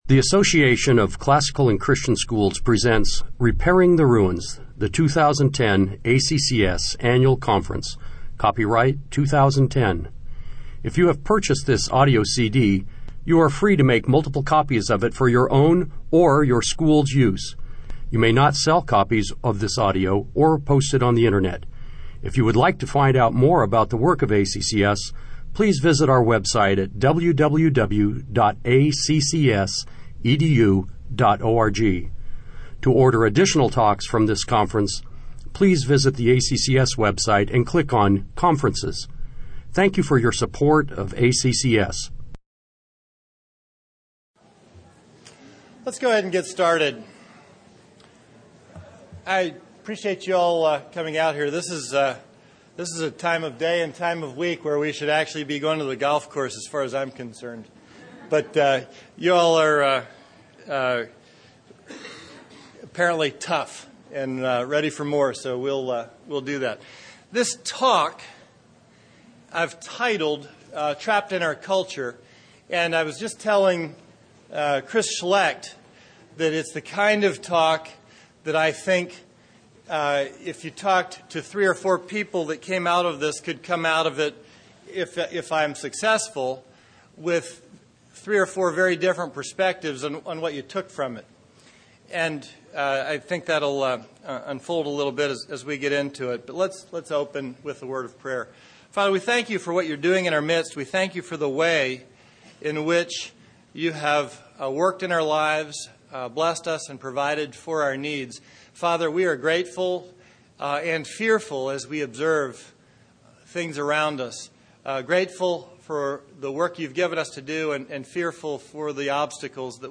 2010 Foundations Talk | 0:55:35 | All Grade Levels, Culture & Faith